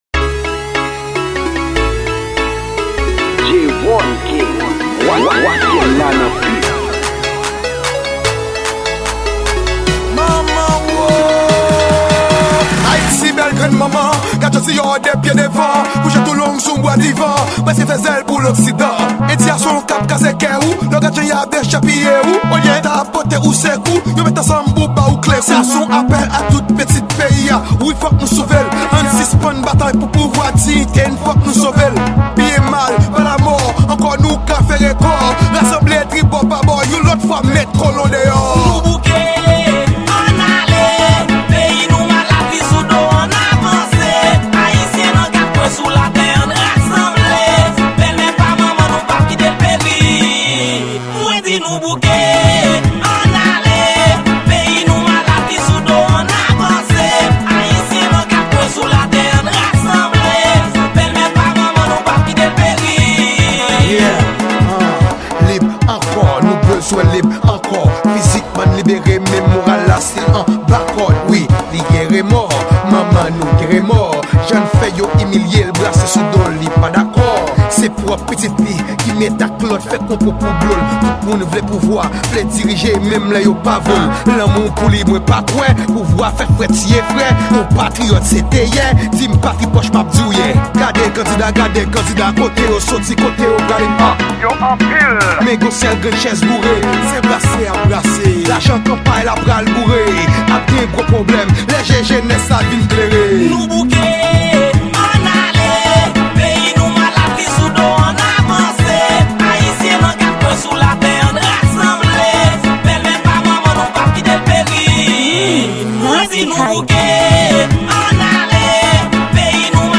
Genre: Racine.